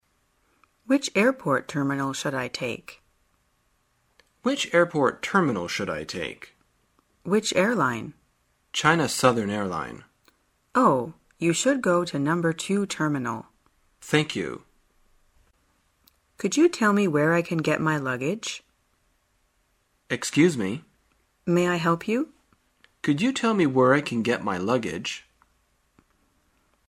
在线英语听力室生活口语天天说 第113期:怎样寻找具体位置的听力文件下载,《生活口语天天说》栏目将日常生活中最常用到的口语句型进行收集和重点讲解。真人发音配字幕帮助英语爱好者们练习听力并进行口语跟读。